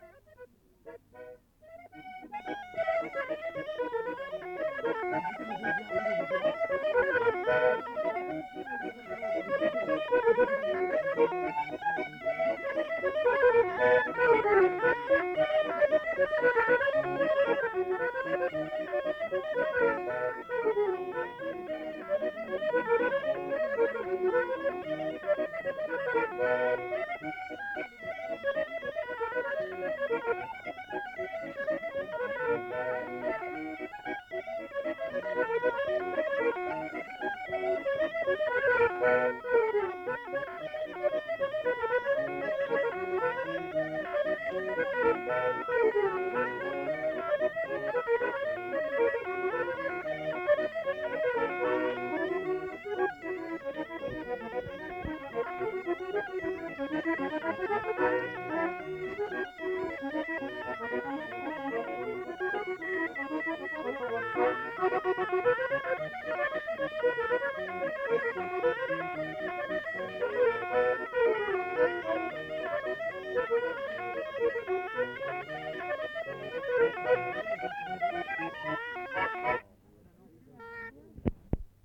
Aire culturelle : Viadène
Département : Aveyron
Genre : morceau instrumental
Instrument de musique : cabrette ; accordéon chromatique
Danse : bourrée